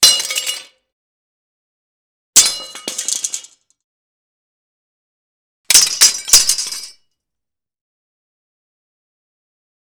weapon
Sword Crashes On Floor